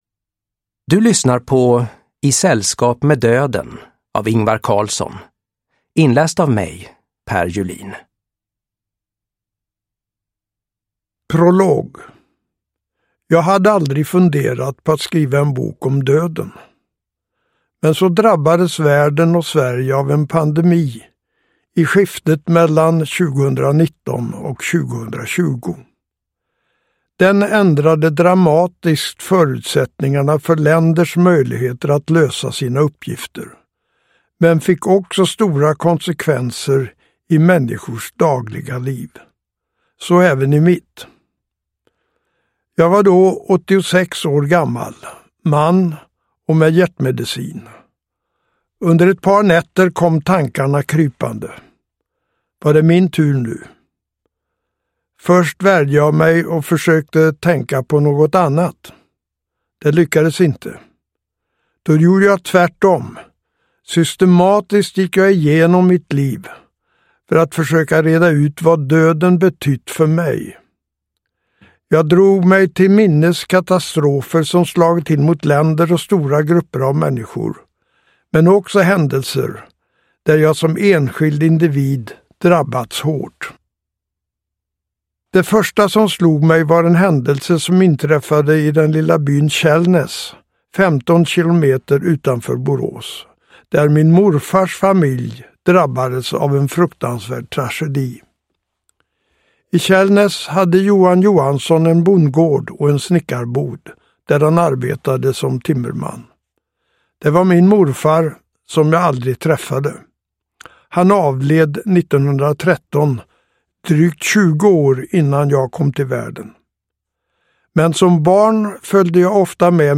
I sällskap med döden – Ljudbok – Laddas ner